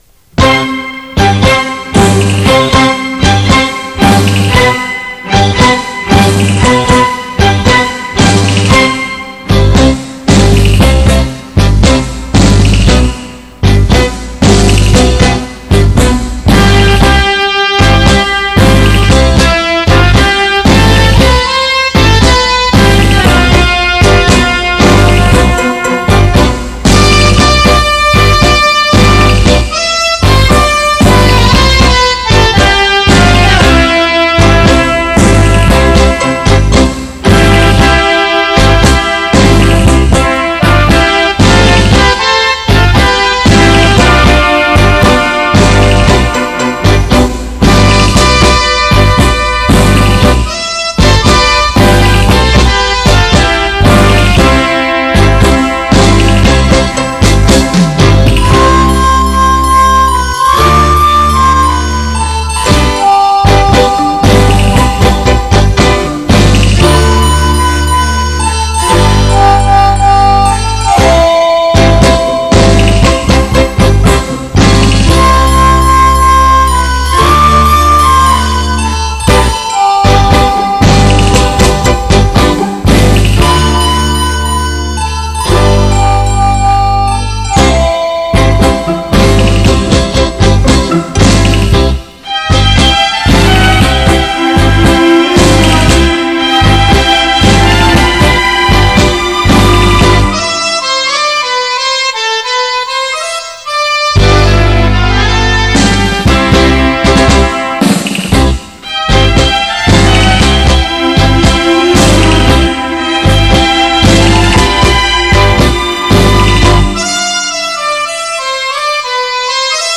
探戈